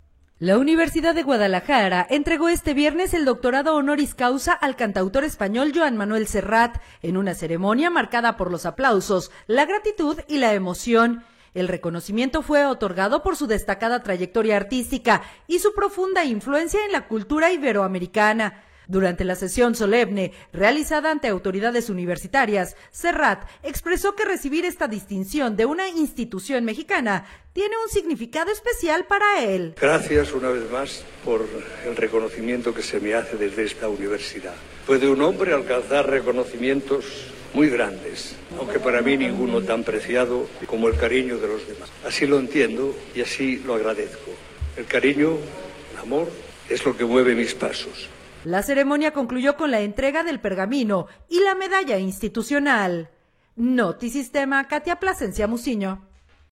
La Universidad de Guadalajara entregó este viernes el Doctorado Honoris Causa al cantautor español Joan Manuel Serrat, en una ceremonia marcada por los aplausos, la gratitud y la emoción.
El reconocimiento fue otorgado por su destacada trayectoria artística y su profunda influencia en la cultura iberoamericana. Durante la sesión solemne, realizada ante autoridades universitarias, Serrat expresó que recibir esta distinción de una institución mexicana tenía un significado especialmente para él.